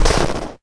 HauntedBloodlines/STEPS Snow, Run 12.wav at main
STEPS Snow, Run 12.wav